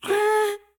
Minecraft Version Minecraft Version snapshot Latest Release | Latest Snapshot snapshot / assets / minecraft / sounds / mob / happy_ghast / ambient2.ogg Compare With Compare With Latest Release | Latest Snapshot